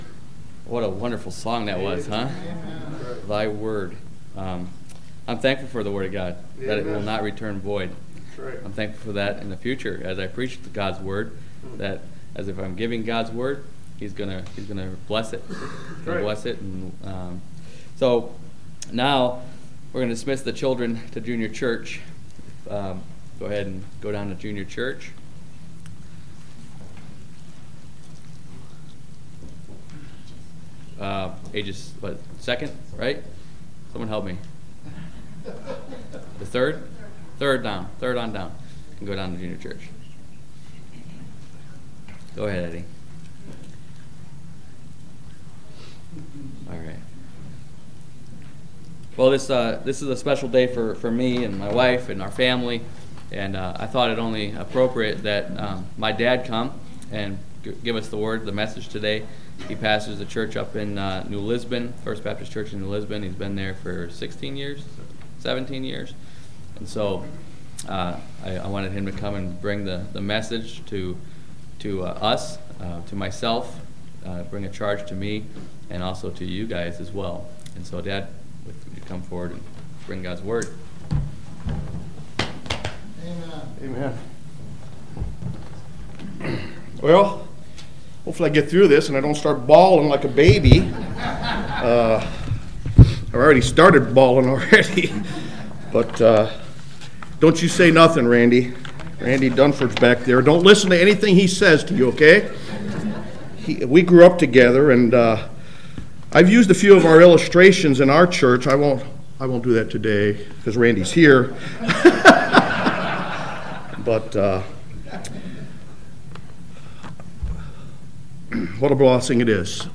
Sermons – Bible Baptist Church of Utica